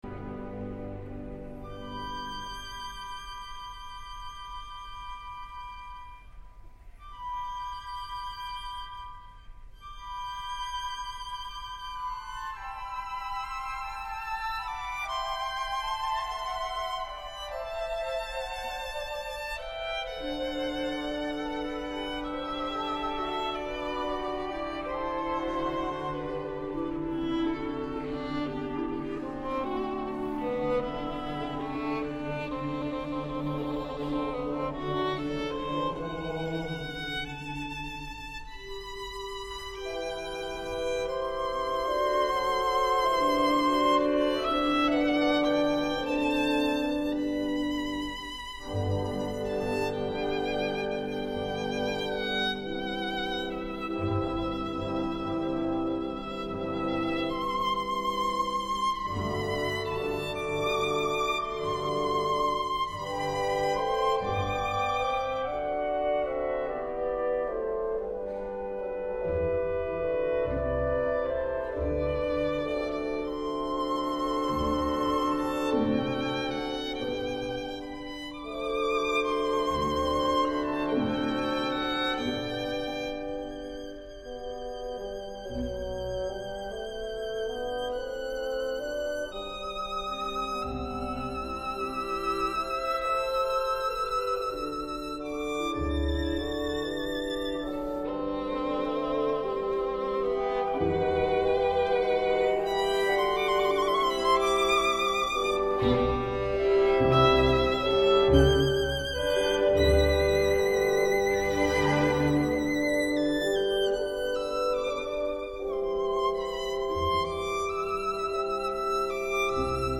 Violin: Beethoven: Missa Solemnis, Sanctus (Benedictus Concertmaster Solo) – Orchestra Excerpts
Christoph Eschenbach,: London Philharmonic Orchestra, 2012 part 1